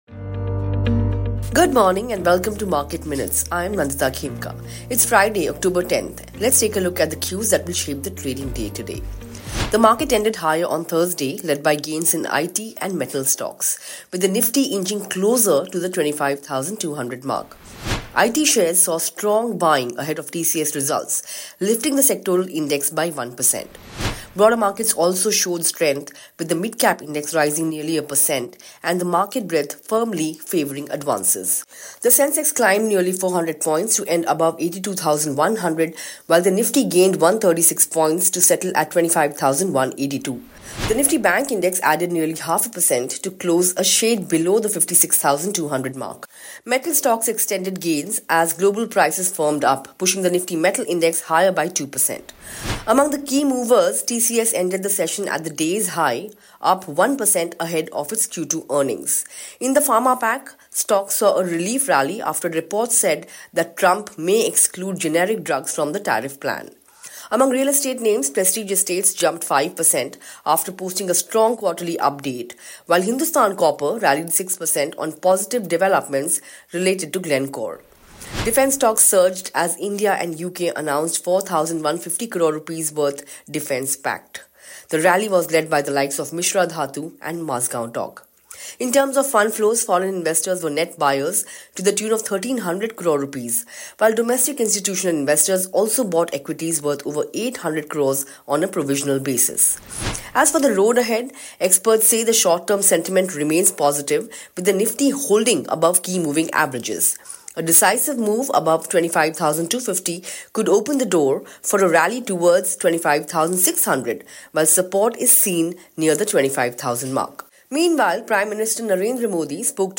Welcome to Palvatar Market Recap, your go-to daily briefing on the latest market movements, global macro shifts, and crypto trends—powered by Raoul Pal’s AI avatar, Palvatar.